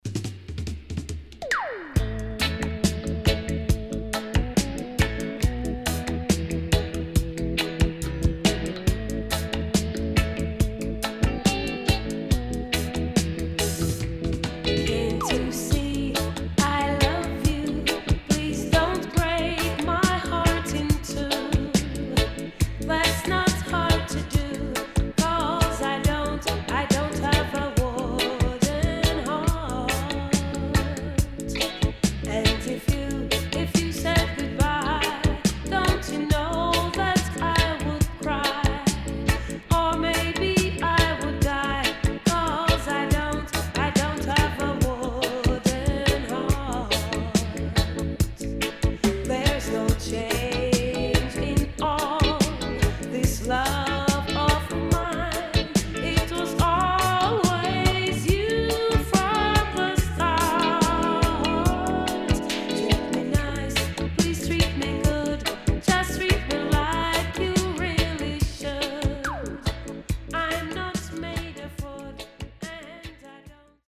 【12inch】
SIDE A:少しチリノイズ入りますが良好です。